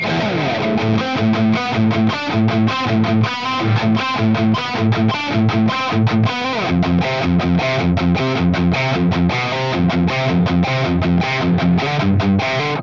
Very simple to get great tones, no switching or options just raw jaw dropping distortion tone.
Metal Riff
RAW AUDIO CLIPS ONLY, NO POST-PROCESSING EFFECTS
Hi-Gain